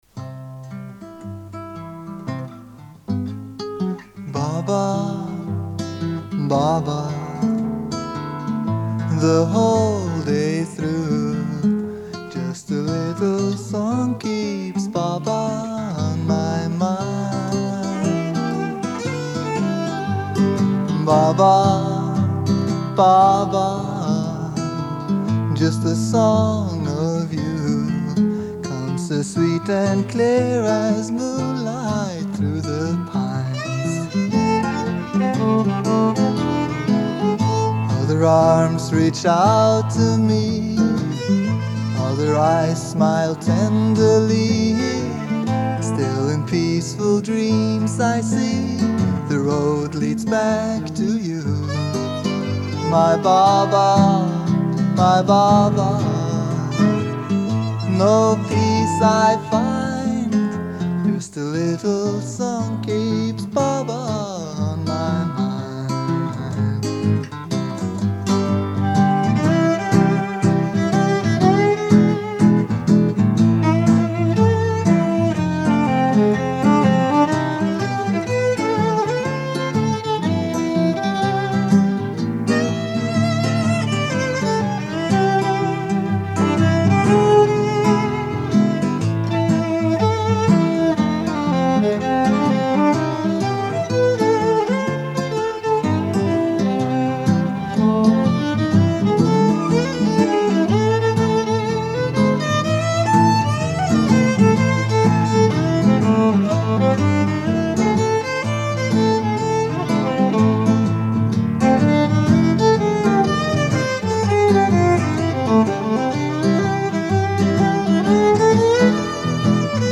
Guitar/Vocals
Viola Solo